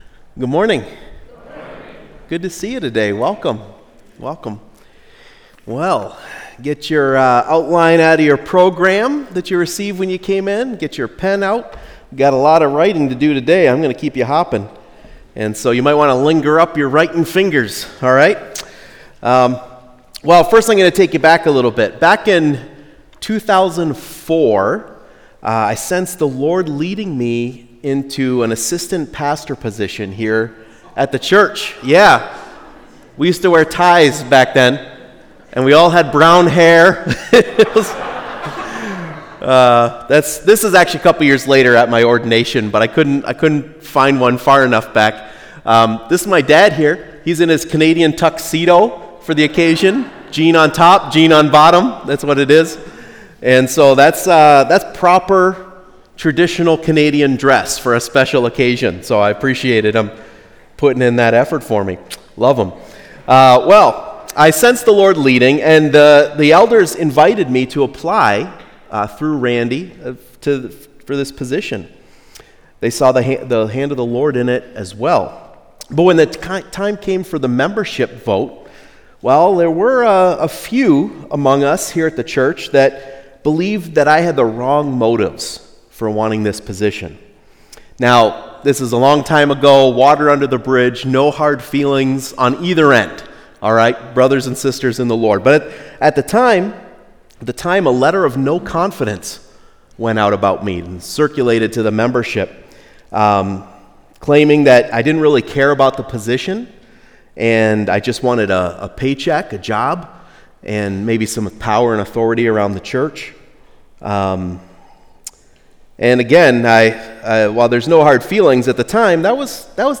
Weekly sermon from Stony Creek Church in Utica, MI.